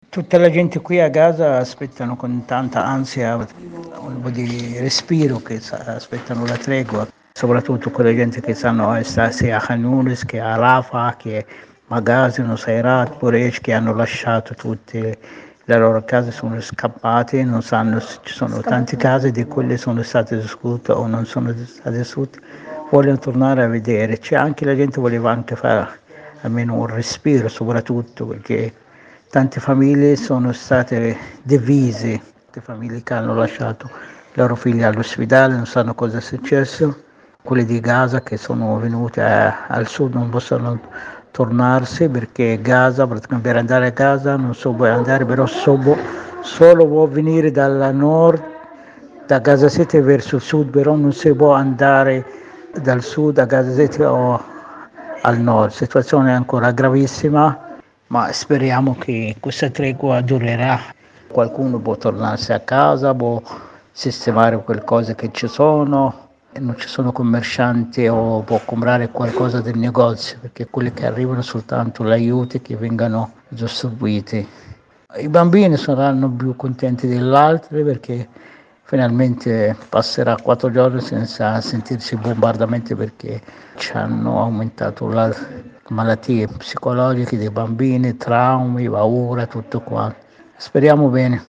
Come stanno vivendo queste ore e questa attesa a Gaza? Il racconto che ci ha mandato un cittadino palestinese da Khan Yunis, nel sud della Striscia: